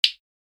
دانلود آهنگ موس 17 از افکت صوتی اشیاء
جلوه های صوتی
برچسب: دانلود آهنگ های افکت صوتی اشیاء دانلود آلبوم صدای کلیک موس از افکت صوتی اشیاء